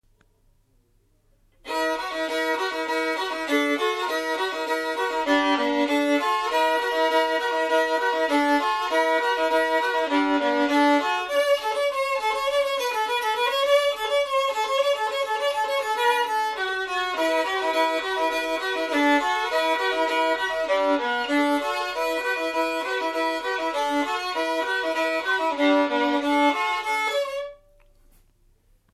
This "homemade" instrument offers- partly through the fine hand varnishing a warm and brigh tone.
Zvukové skúšky